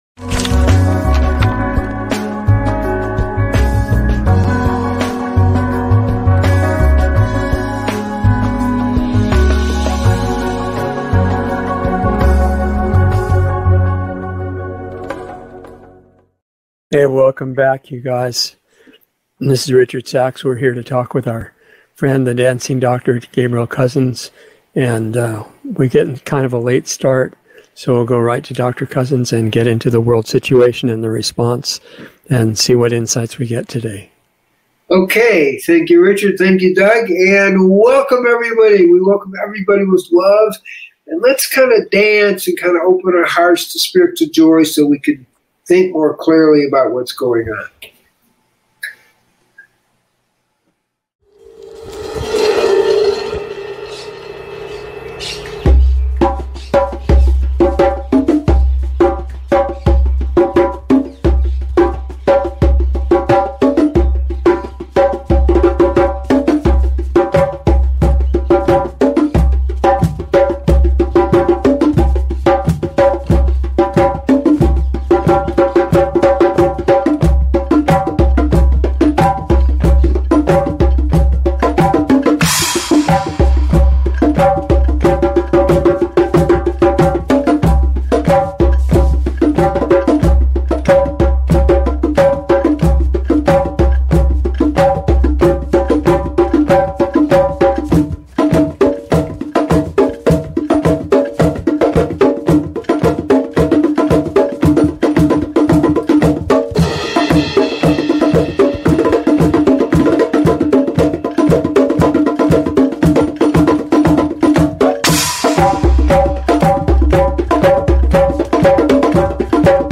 Dialogs
A new LIVE series